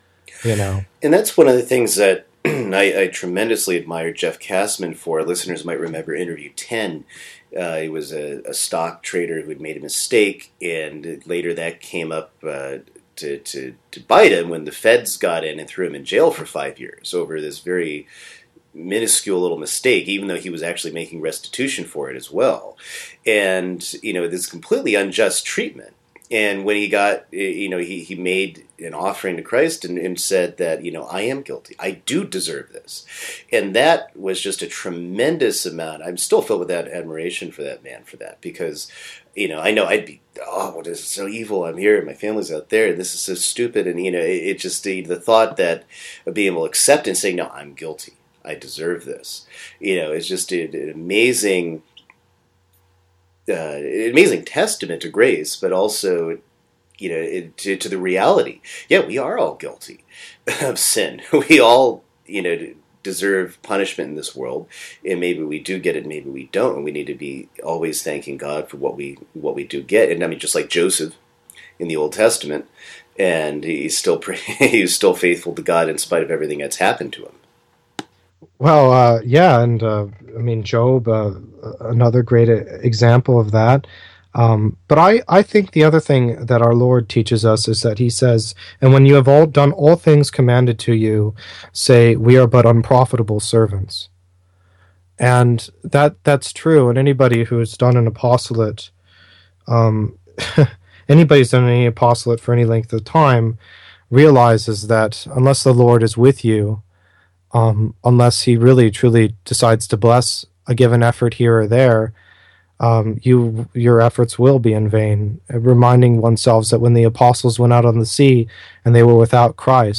interview_016_part4.mp3